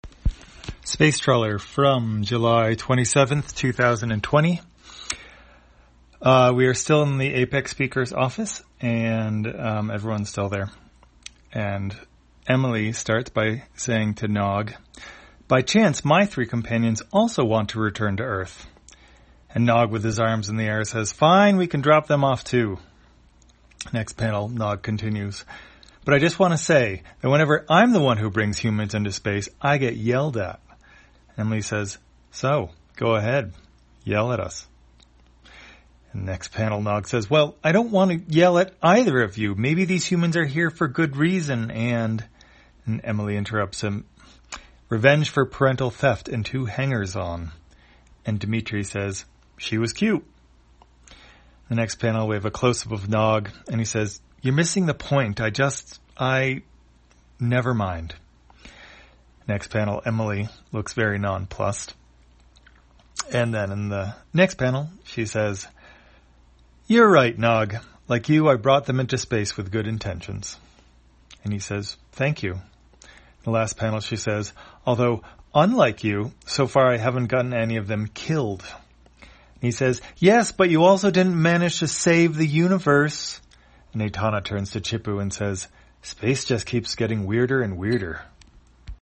Spacetrawler, audio version For the blind or visually impaired, July 27, 2020.